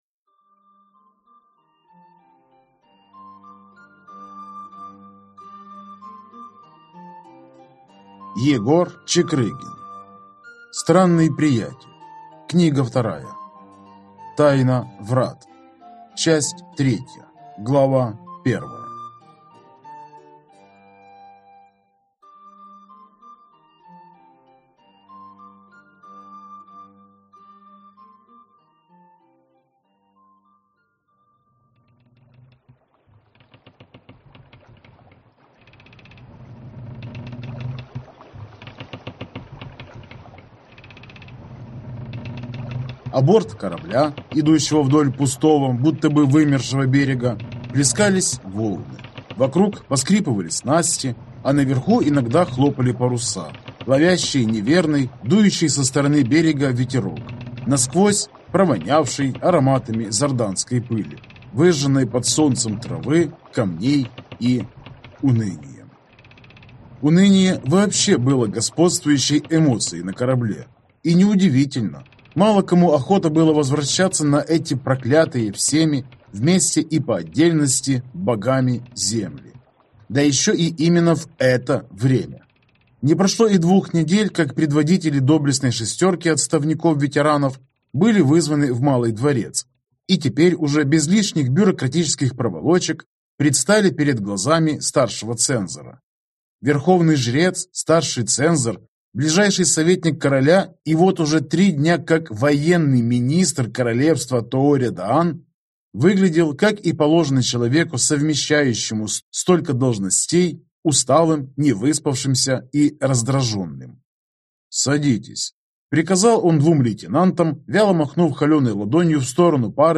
Аудиокнига Странный приятель. Тайна Врат | Библиотека аудиокниг